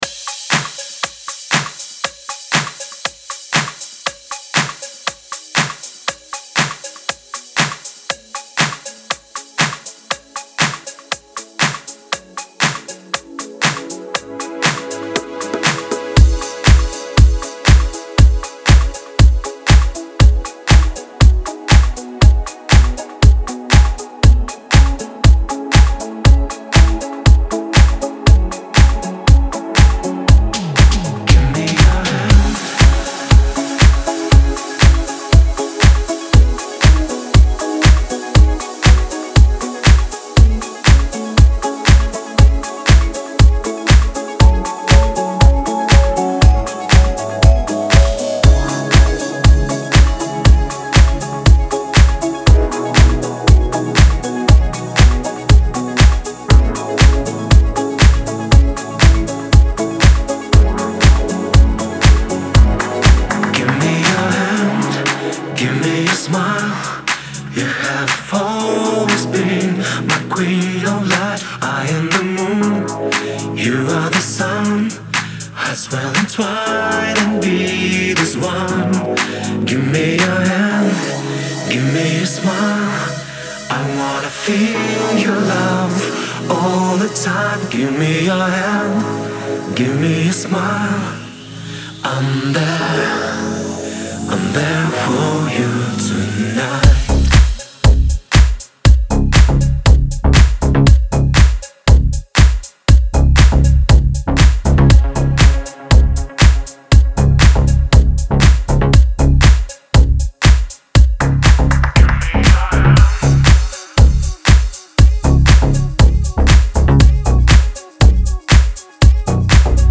это энергичная и мелодичная композиция в жанре поп